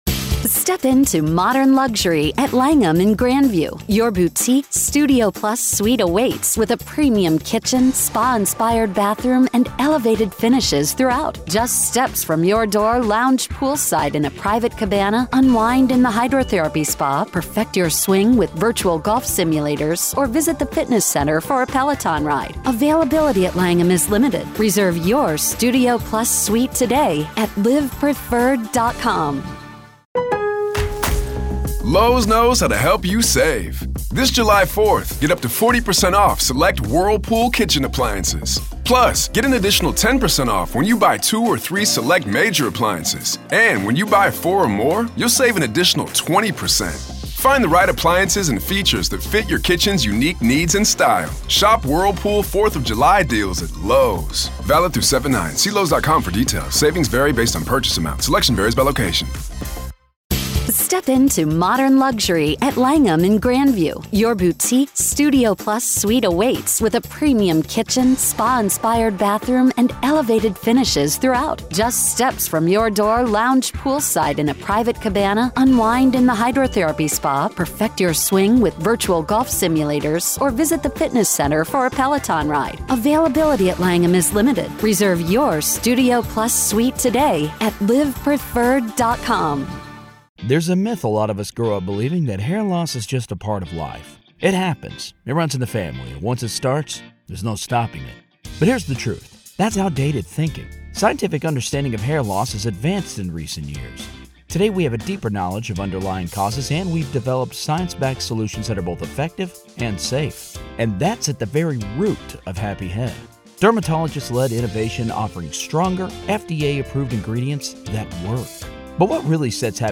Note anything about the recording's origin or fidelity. This gripping podcast series transports you to the heart of the legal proceedings, providing exclusive access to the in-court audio as the prosecution and defense lay out their arguments, witnesses testify, and emotions run high.